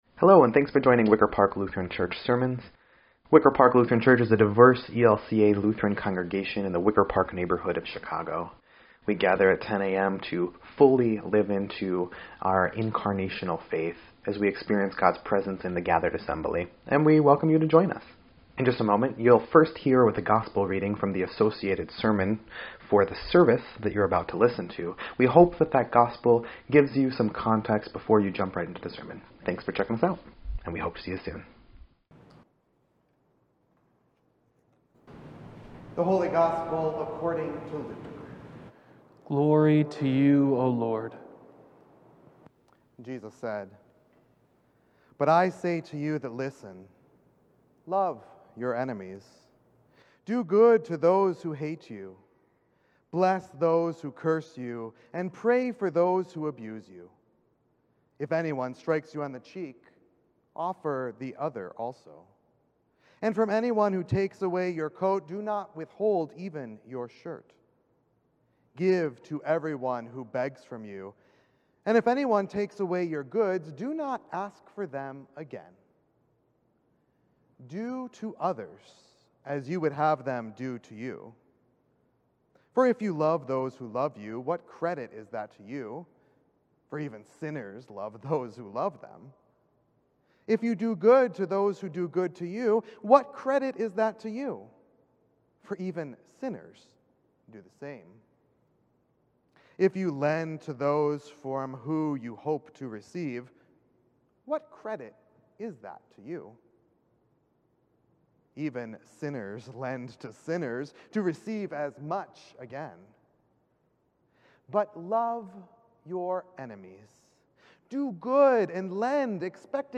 2.20.22-Sermon_EDIT.mp3